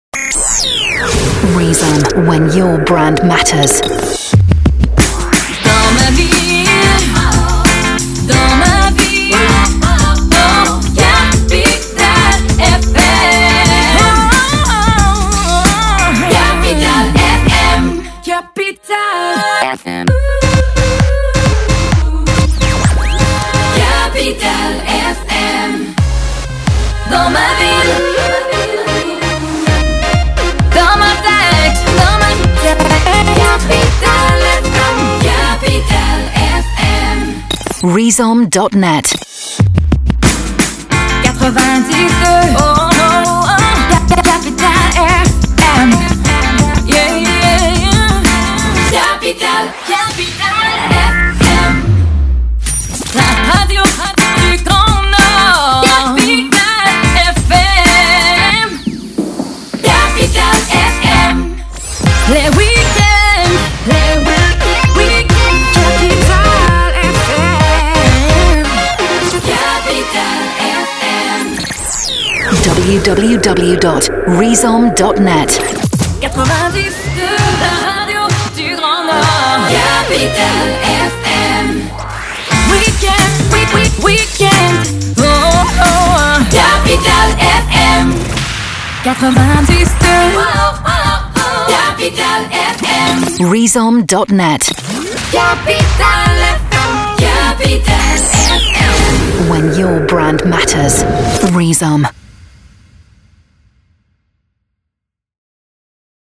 Renouveler la marque avec des jingles chantés en repiquage.